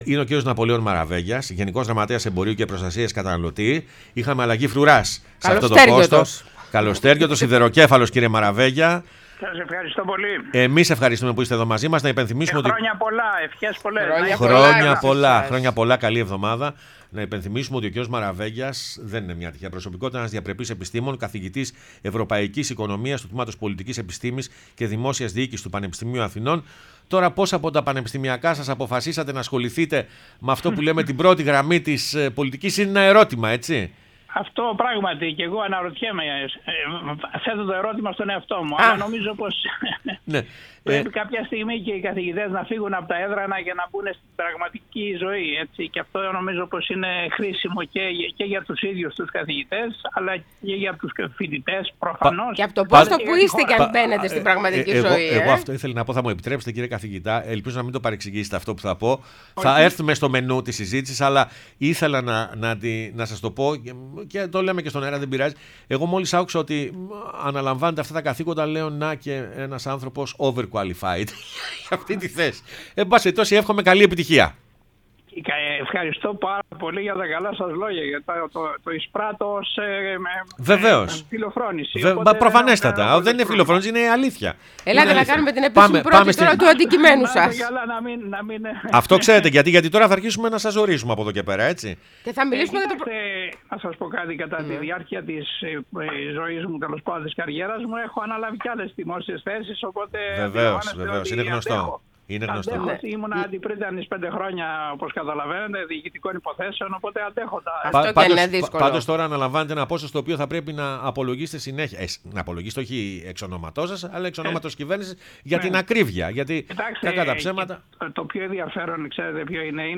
Ο Ναπολέων Μαραβέγιας, ο νέος Γενικός Γραμματέας Εμπορίου και Προστασίας Καταναλωτή – Καθηγητής Ευρωπαϊκής Οικονομίας Τμήματος Πολιτικής Επιστήμης και Δημόσιας Διοίκησης στο Πανεπιστήμιο Αθηνών, μίλησε στην εκπομπή “Πρωινές Διαδρομές”